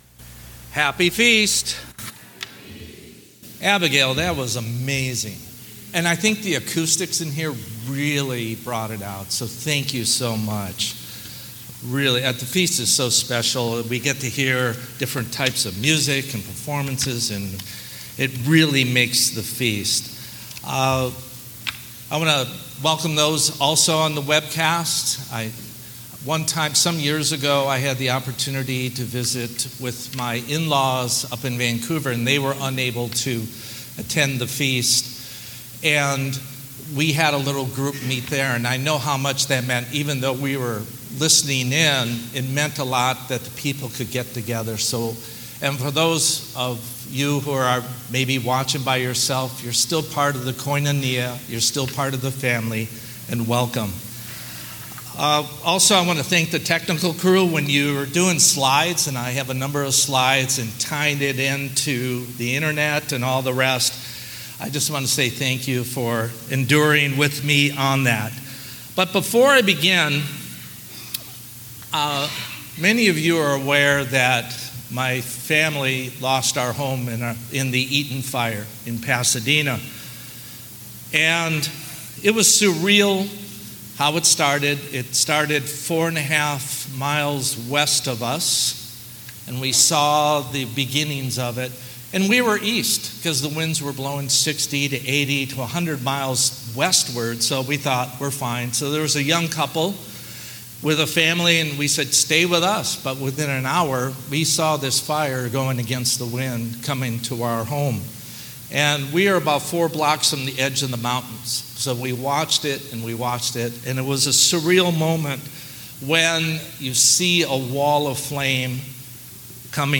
Given in Temecula, California